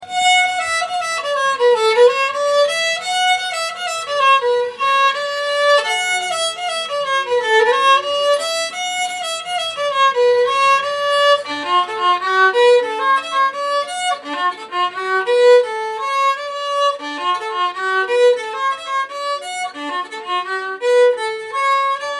Engelska